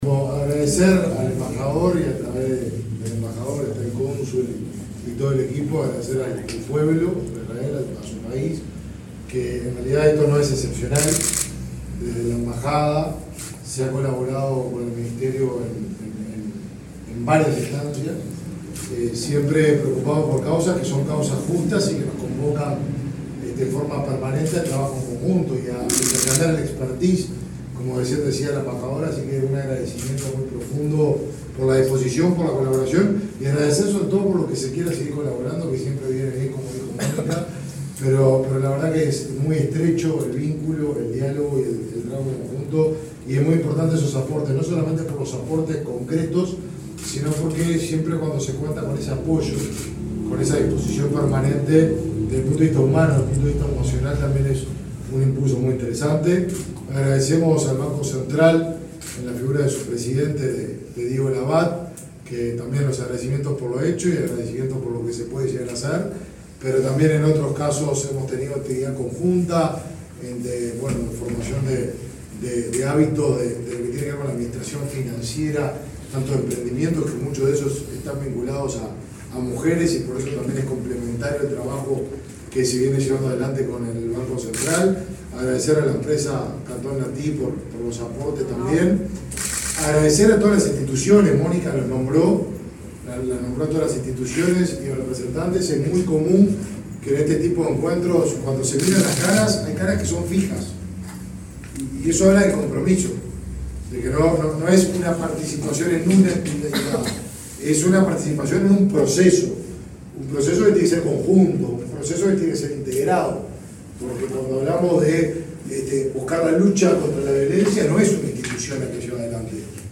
Palabras del ministro de Desarrollo Social, Martín Lema
Palabras del ministro de Desarrollo Social, Martín Lema 15/12/2022 Compartir Facebook X Copiar enlace WhatsApp LinkedIn El ministro de Desarrollo Social, Martín Lema, participó este jueves 15 en Montevideo, en la inauguración de la sede descentralizada de atención a mujeres en situación de violencia.